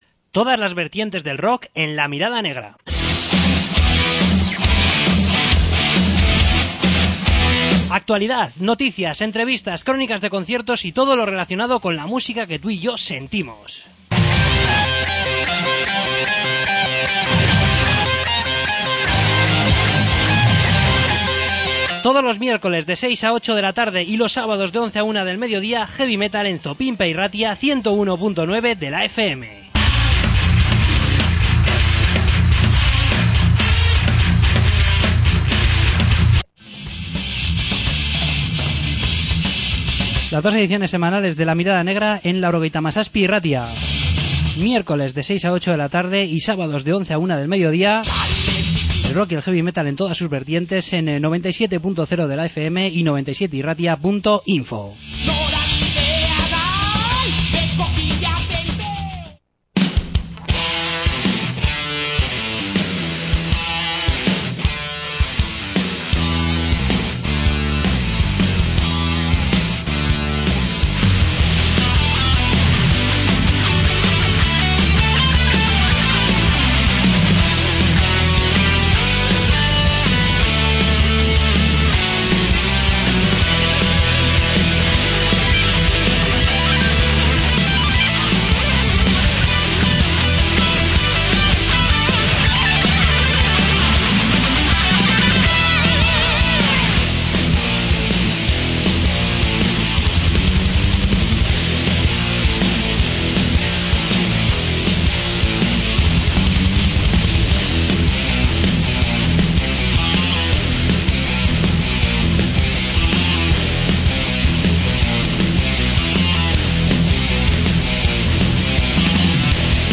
Entrevista con Fuck Divsision